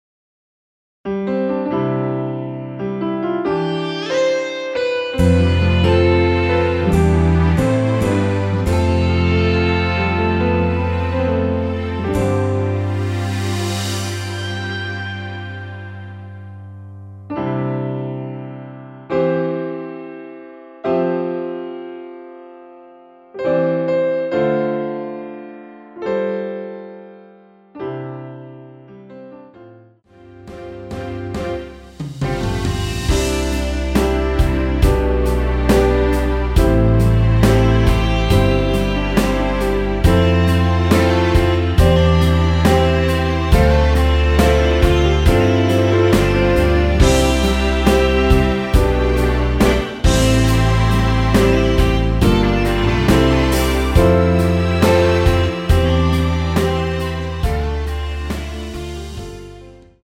(+2)올린 MR 입니다.(미리듣기 참조)
앞부분30초, 뒷부분30초씩 편집해서 올려 드리고 있습니다.
중간에 음이 끈어지고 다시 나오는 이유는